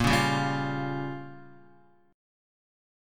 A#m6 Chord